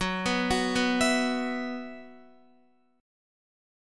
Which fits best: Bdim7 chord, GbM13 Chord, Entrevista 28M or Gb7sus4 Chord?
Gb7sus4 Chord